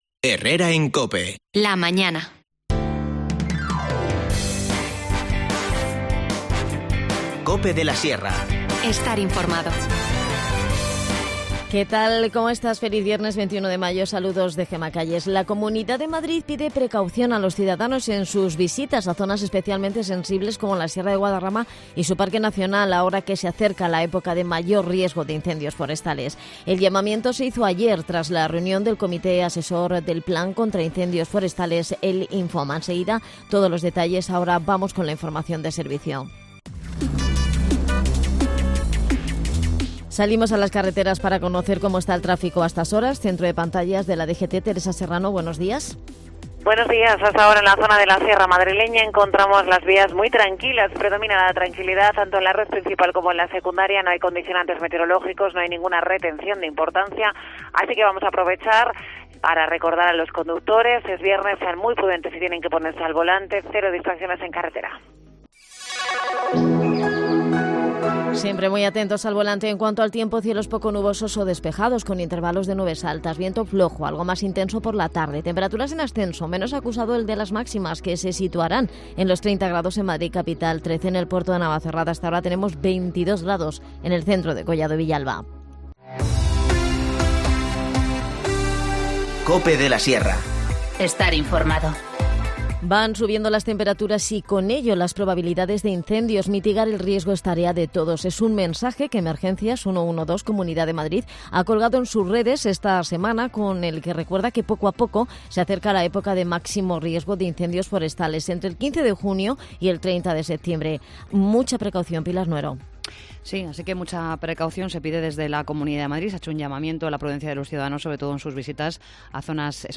La pandemia en India hace abandonar a Carlos Soria su reto de conquistar el Dhaulagiri. En el espacio de Deportes en Herrera en COPE escuchamos al alpinista y su decisión al respecto.